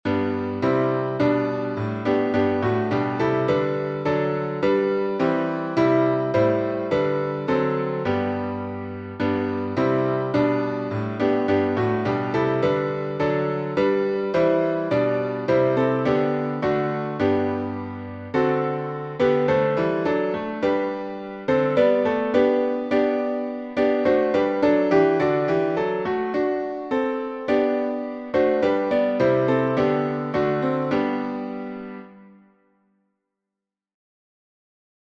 #5026: Guide Me, O Thou Great Jehovah — refrain echoes | Mobile Hymns
Key signature: G major (1 sharp) Time signature: 4/4
Guide_Me_O_Thou_Great_Jehovah_echo.mp3